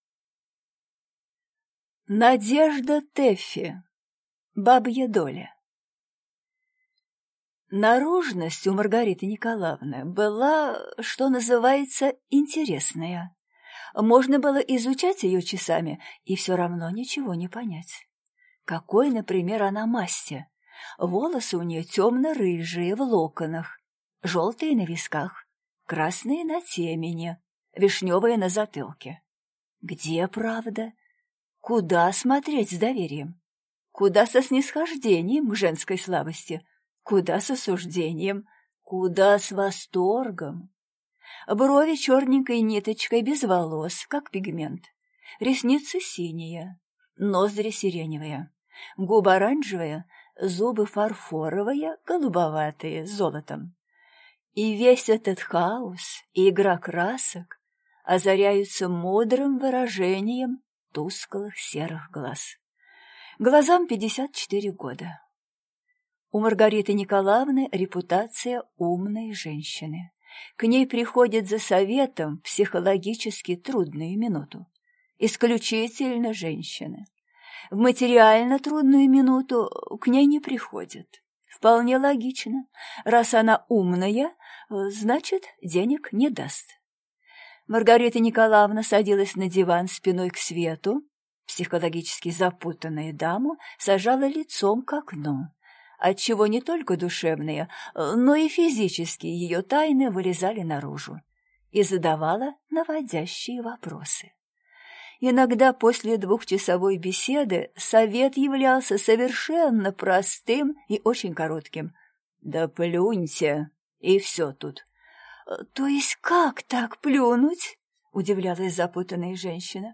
Aудиокнига Бабья доля
Читает аудиокнигу